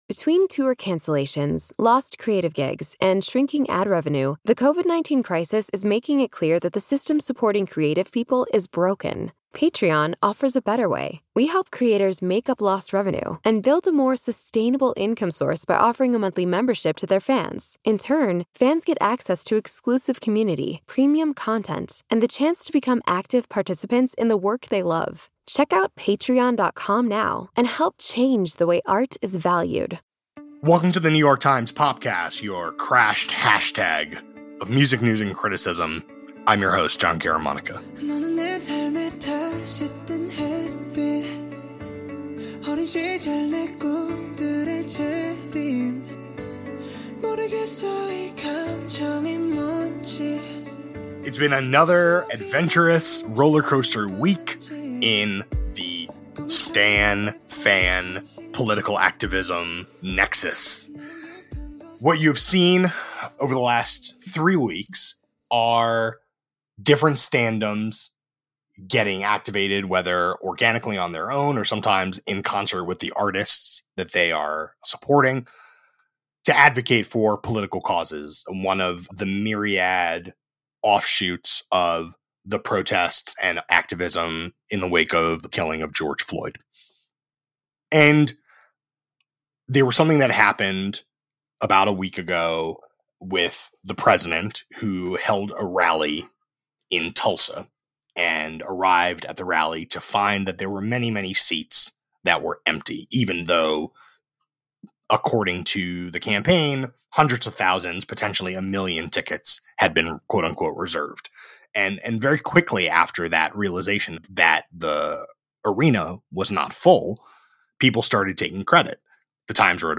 A conversation about the activism feedback loop between stans and the stars they obsess over.